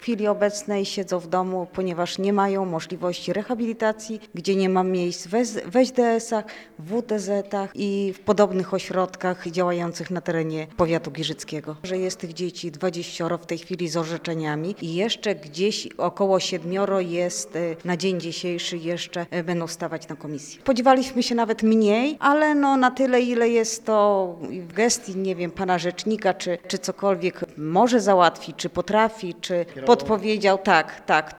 Adam Bodnar spotkał się w środę (16.05) z mieszkańcami w tamtejszym ratuszu.
Opiekunowie osób niepełnosprawnych skarżyli się, że są pozostawieni sami sobie, na pastwę losu.